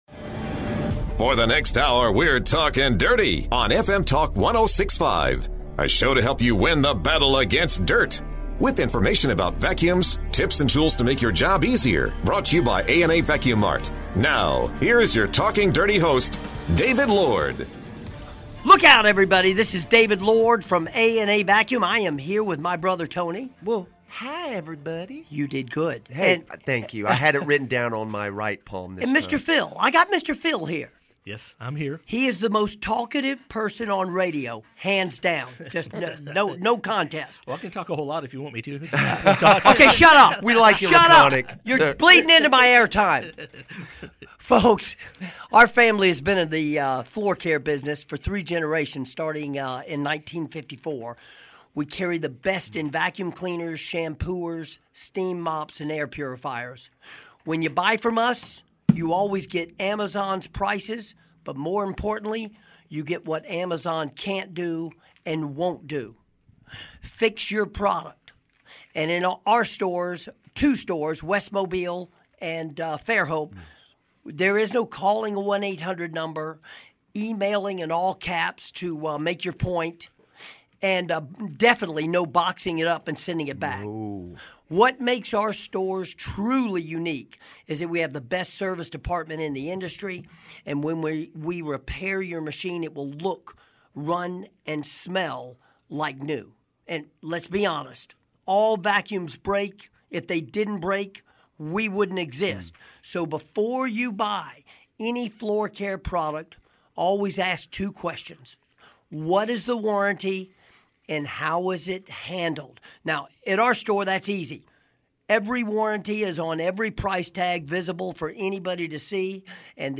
They interview